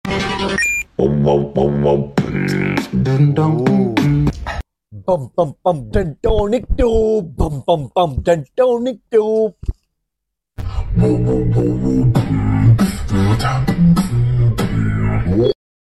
sound challenge beatbox